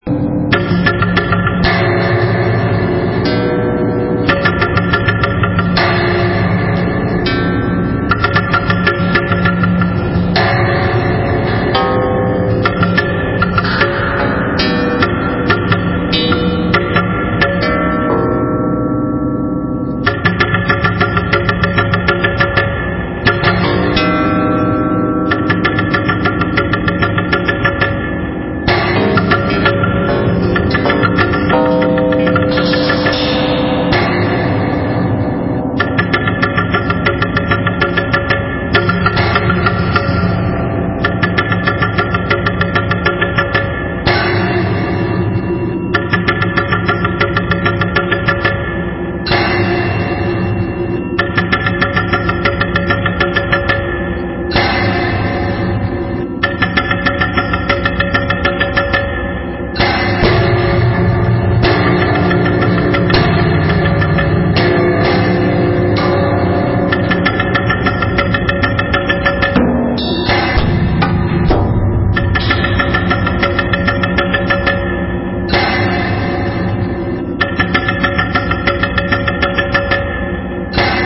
percussive instruments, rotors, electronics